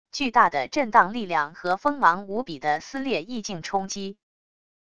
巨大的震荡力量和锋芒无比的撕裂意境冲击wav音频